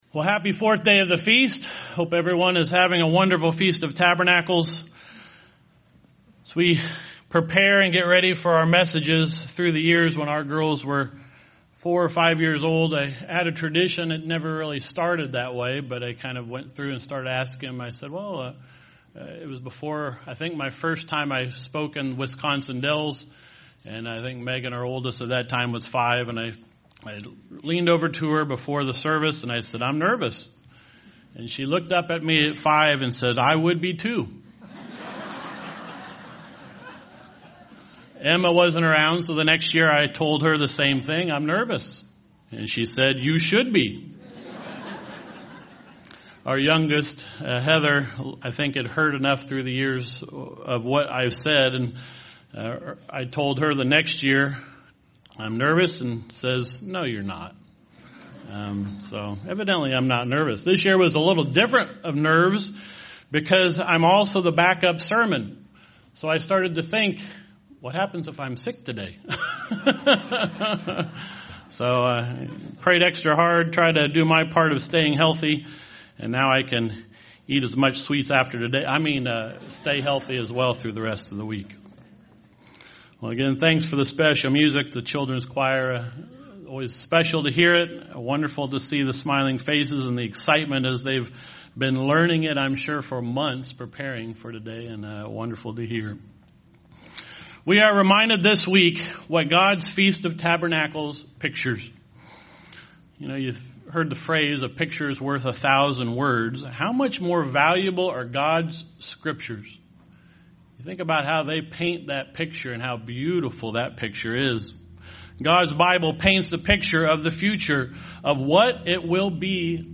This sermon was given at the Branson, Missouri 2019 Feast site.